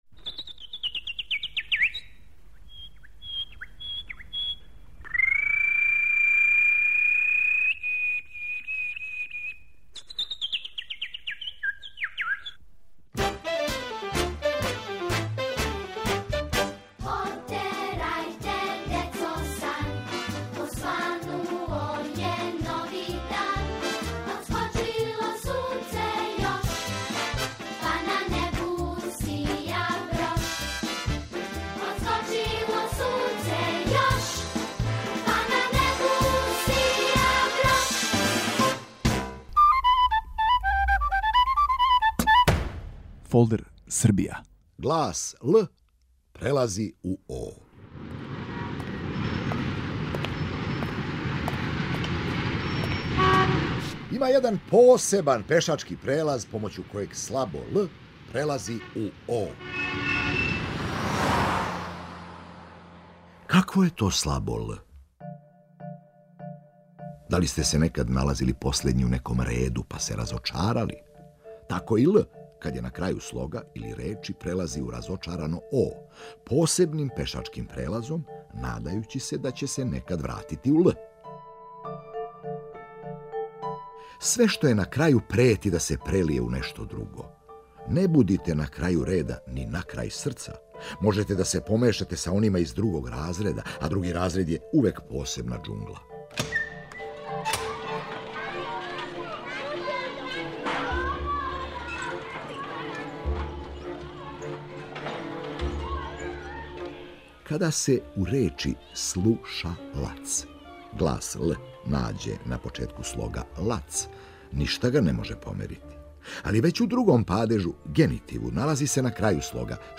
У серијалу ФОЛДЕР СРБИЈА: "Глас Л прелази у О", текст: Добривоје Станојевић. Са вама кроз Србију путује Бранимир Брстина, глумац.